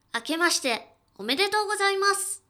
ボイス
女性挨拶